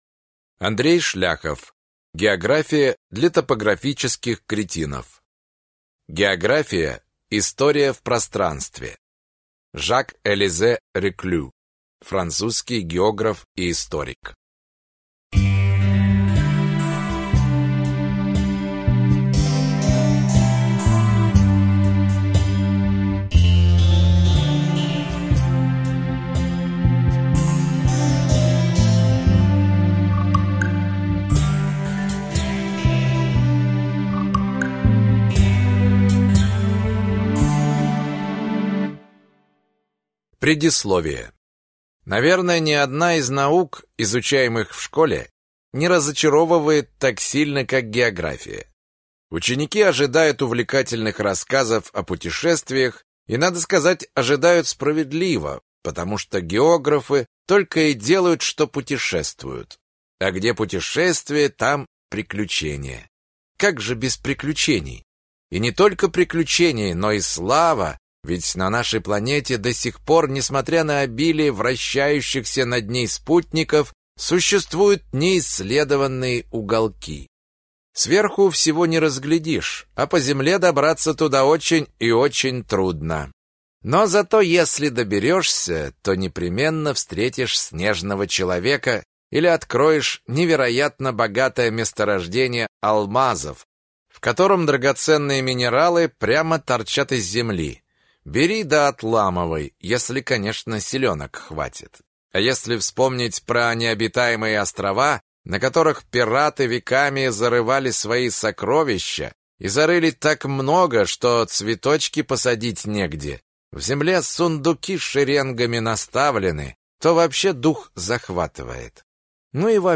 Аудиокнига География для топографических кретинов | Библиотека аудиокниг